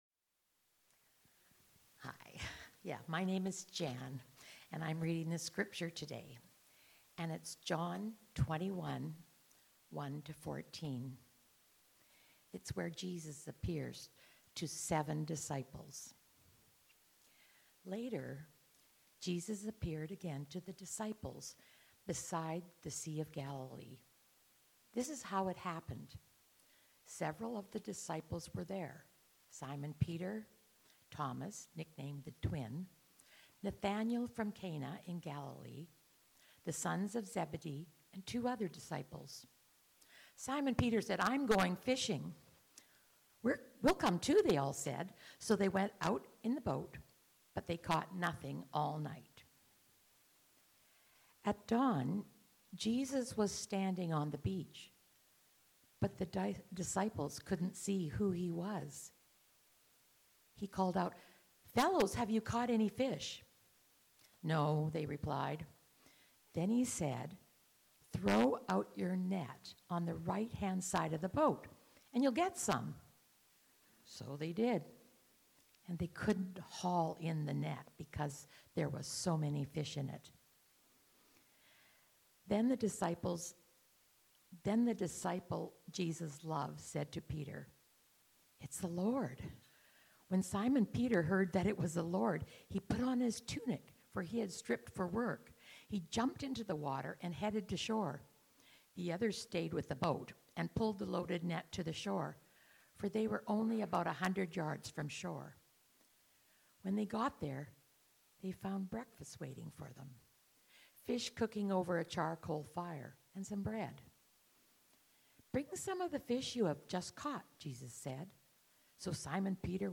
Messages | Nanaimo Alliance Church